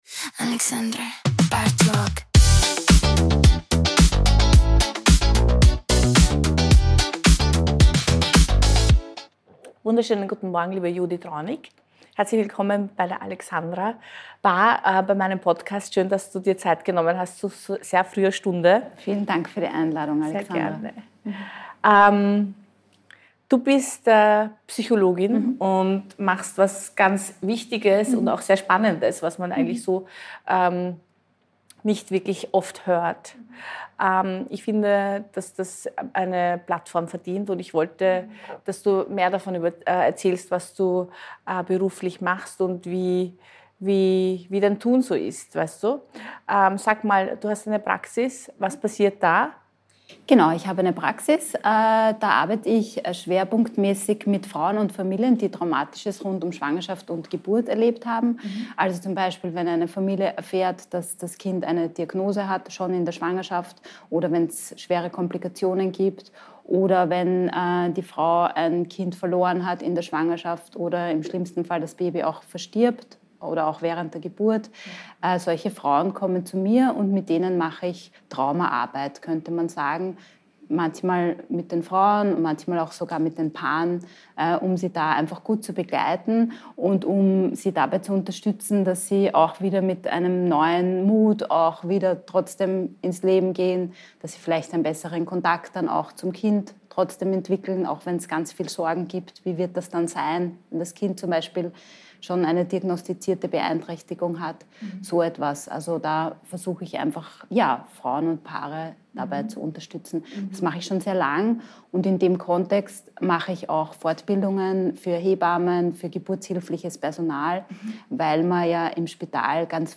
Zwischen Drink und Dialog entstehen Gespräche, die Tiefe haben - mal leise, mal laut, aber es immer wird Tacheles geredet. Scharf serviert und eiskalt nachgeschenkt mit einem Spritzer Humor, Cheers!